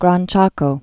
(grän chäkō)